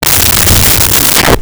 Creature Growl 06
Creature Growl 06.wav